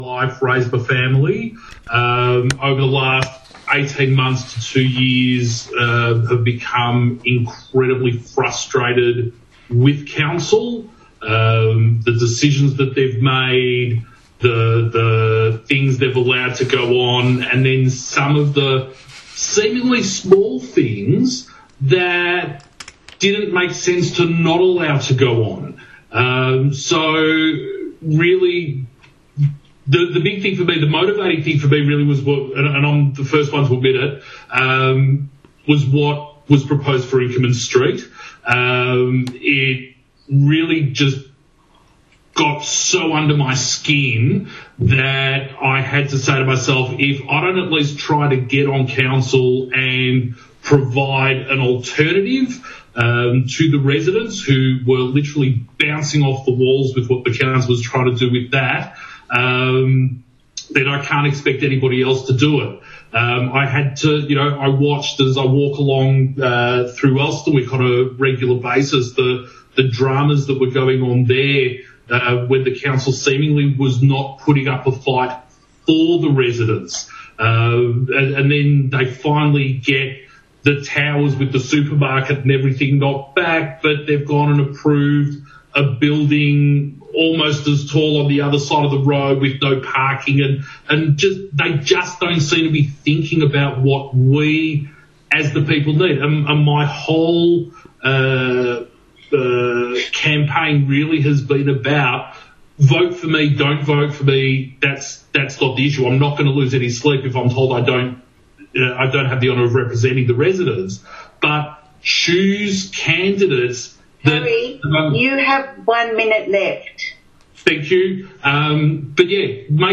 For those residents who were not in attendance at Tuesday night’s Camden Candidates Forum, and would like to know what was said, we have uploaded several audios from the candidates initial 3 minute address.